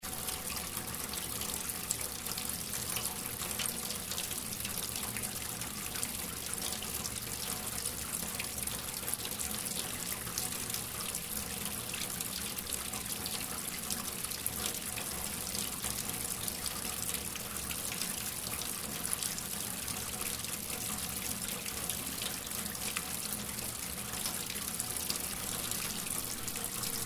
Water Sound Effects
TapBasin_05.mp3